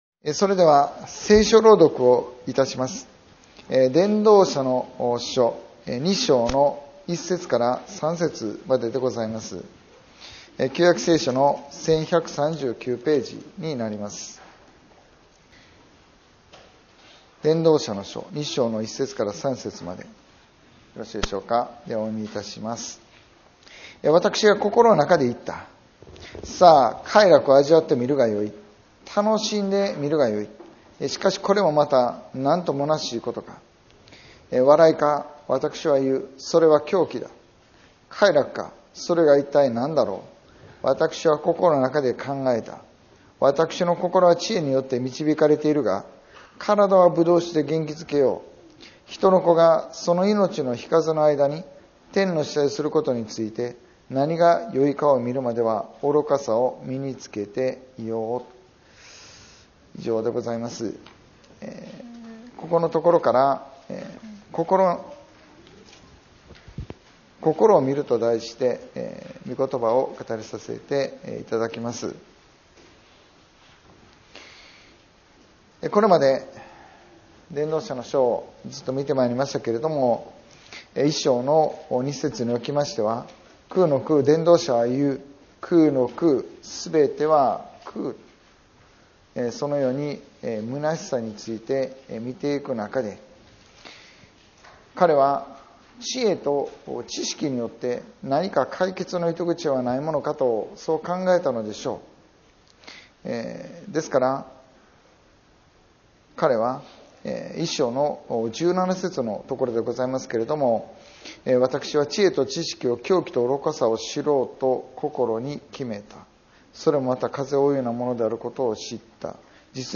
2026年1月25日 礼拝説教「心を見る」